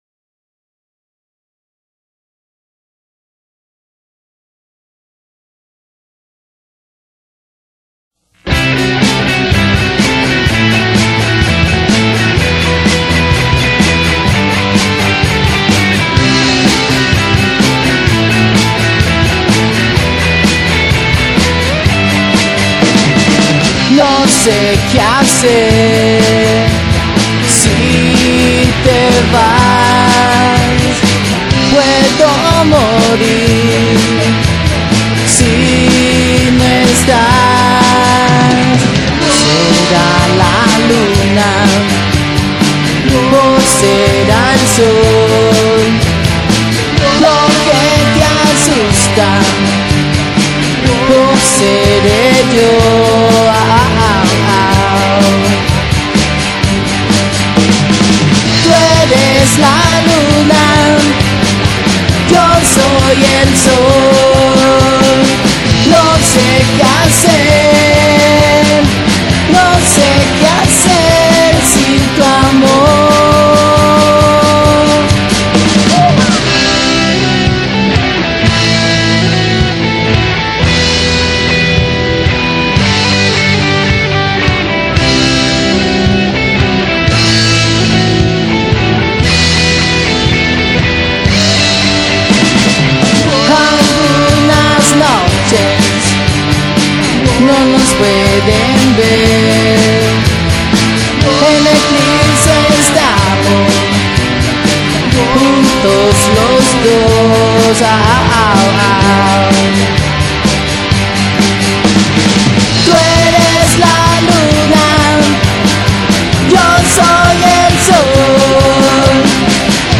Músico, cantautor y multi-instrumentista
Rock latino